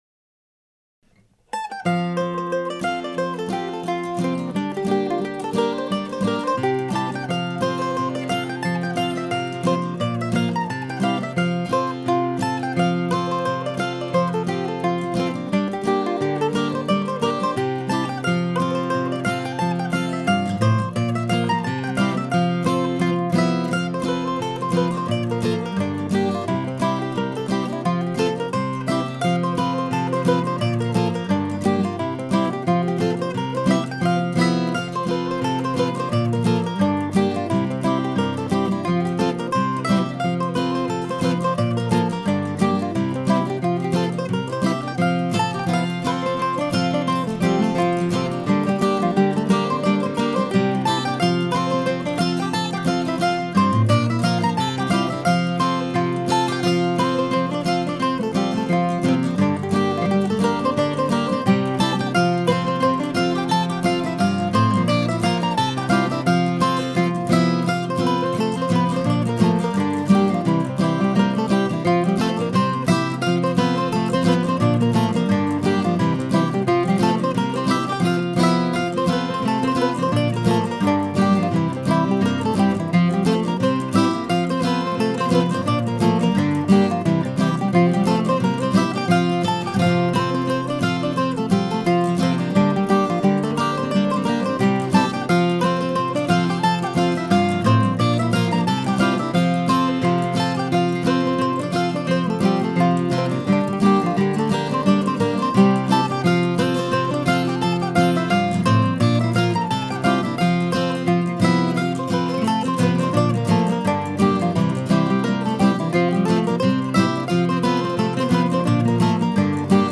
Recorded this morning, Gibson mandolin, Martin guitar.